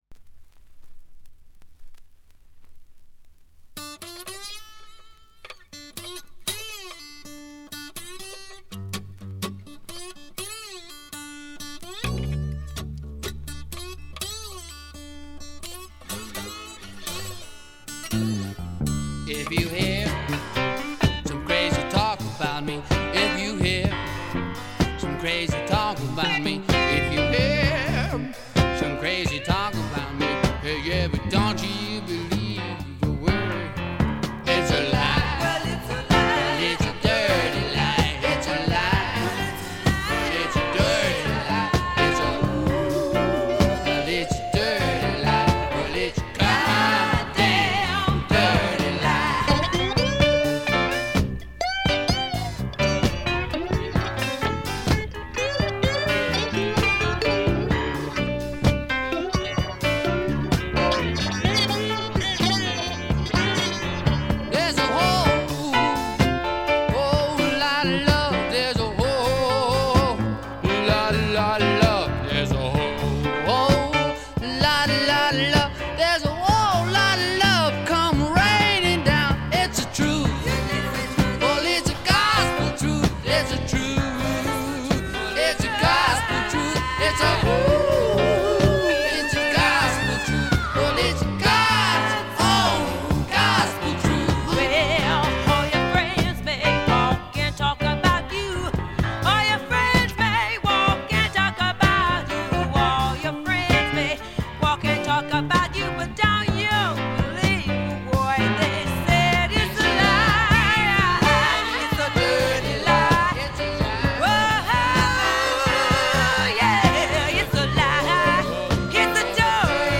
ごくわずかなノイズ感のみ。
独特のしゃがれた渋いヴォーカルで、スワンプ本線からメローグルーヴ系までをこなします。
試聴曲は現品からの取り込み音源です。